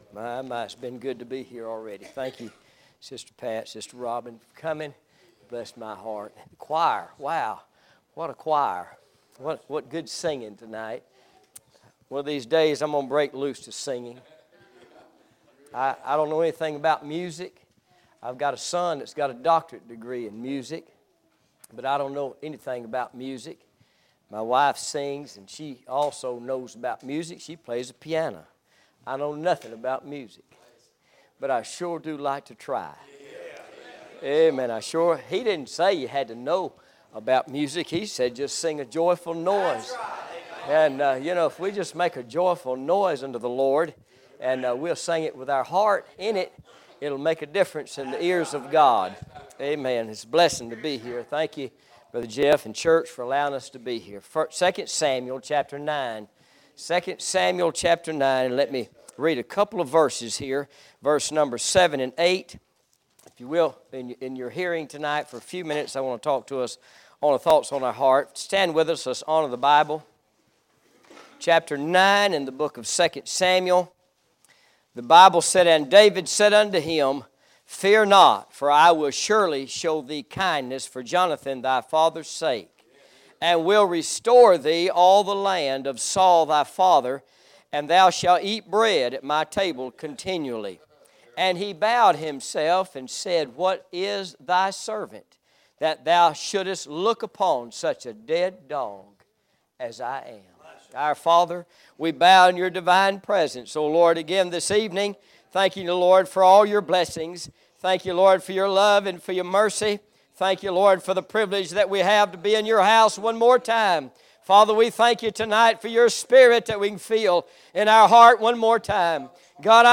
Series: Spring Revival 2017